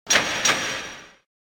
key_collect.ogg